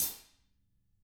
Index of /90_sSampleCDs/ILIO - Double Platinum Drums 1/CD2/Partition D/THIN A HATR